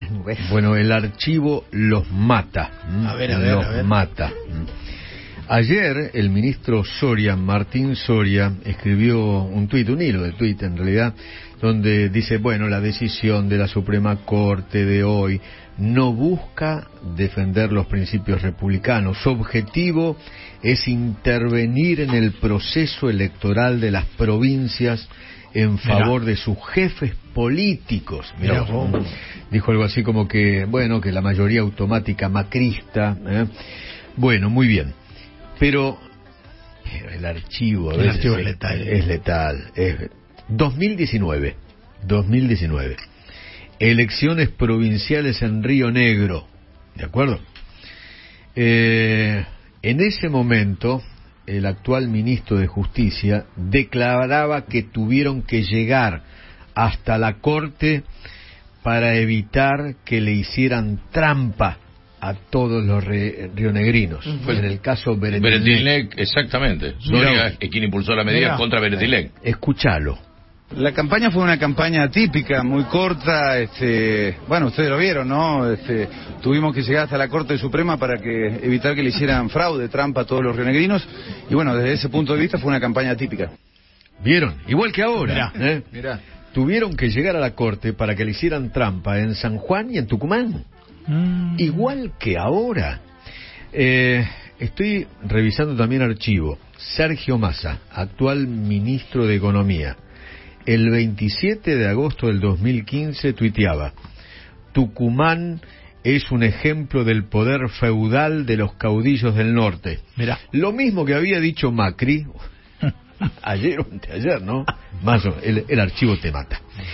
El conductor de Alguien Tiene Que Decirlo se refirió las repercusiones en el kirchnerismo, tras la decisión de la Corte Suprema de suspender las elecciones en San Juan y Tucumán.
-“Es el caso iniciado por Soria contra Alberto Weretilneck. Audio del ministro de justicia”